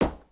sounds / material / human / step / t_wood2.ogg
t_wood2.ogg